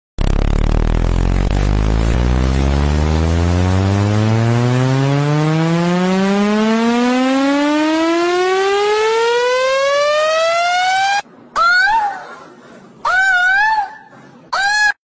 جبتلكم اقوا صوت لي تنظيف السماعه من الغبار